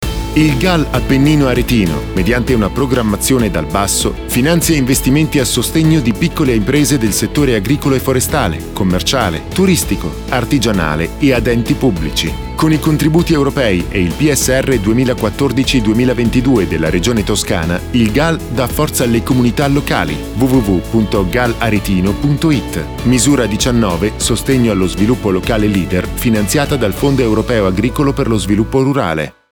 Radio Italia 5  spot n. 3